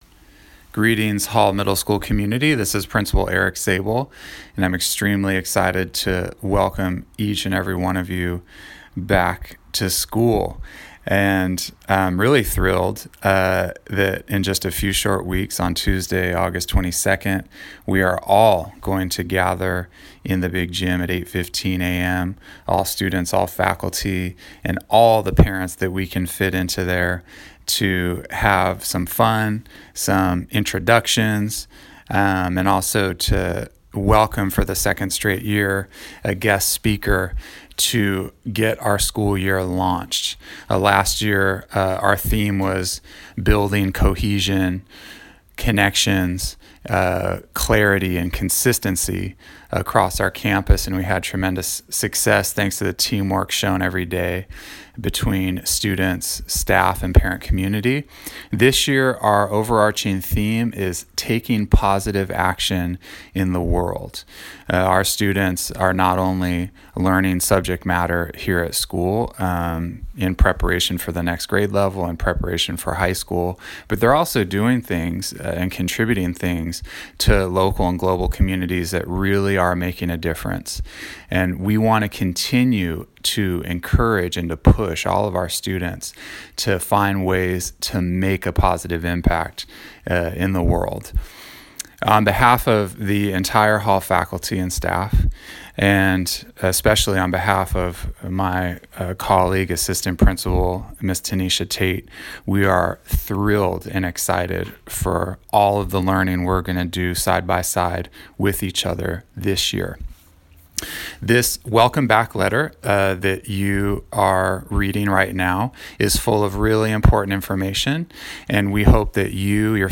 welcoming message